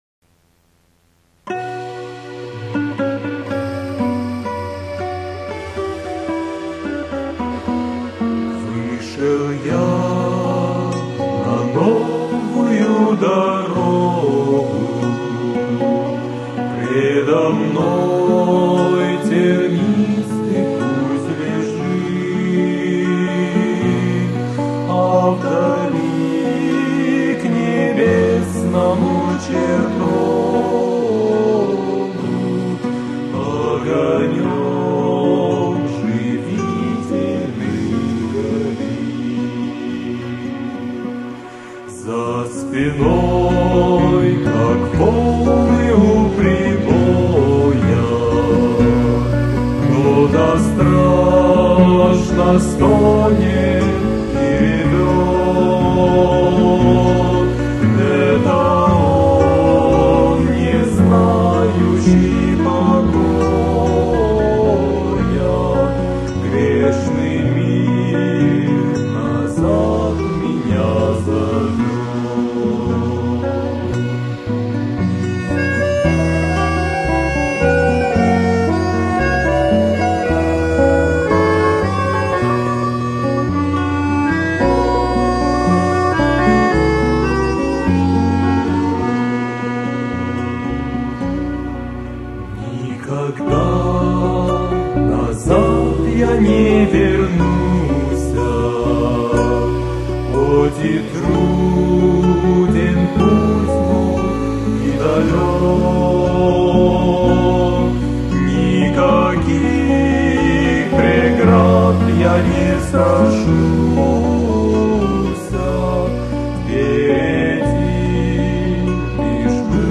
минус / 64 кбит/с 3